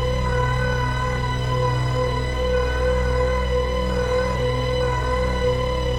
Index of /musicradar/dystopian-drone-samples/Non Tempo Loops
DD_LoopDrone2-A.wav